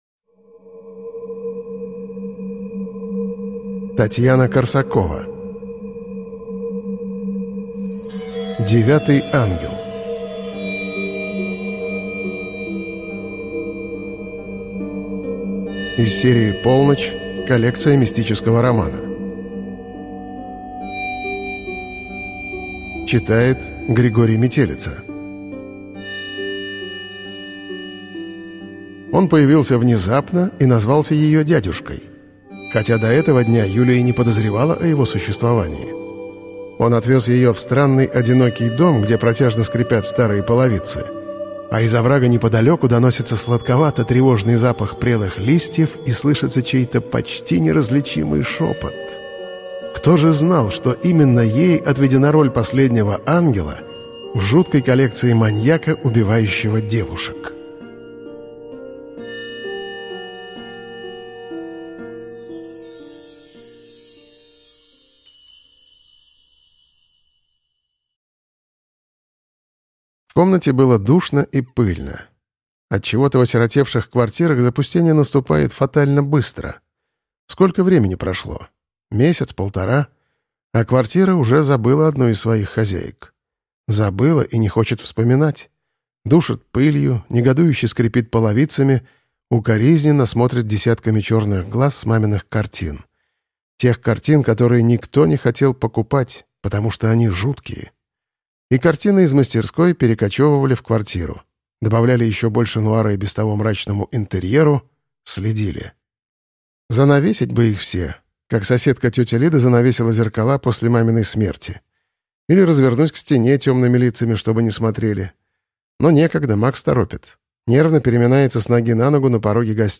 Аудиокнига Девятый ангел | Библиотека аудиокниг